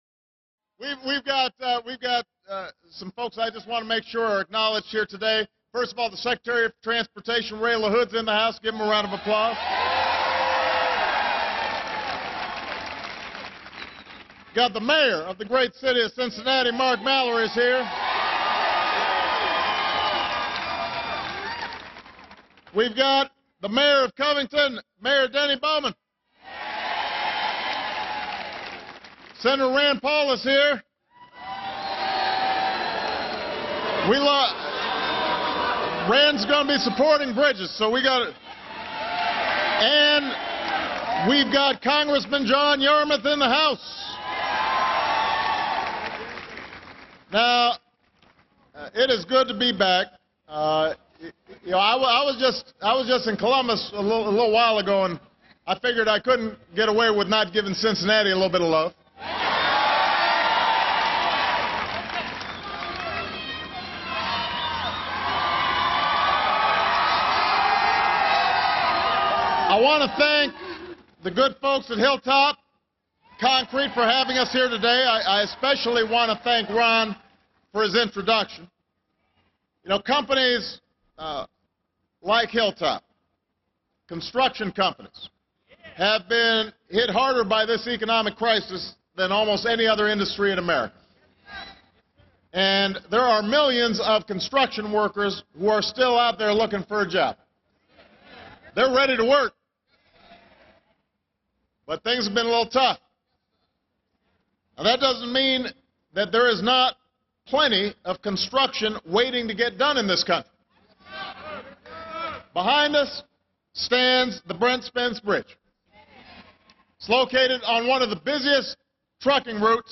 U.S. President Barack Obama speaks about the American Jobs Act and taxation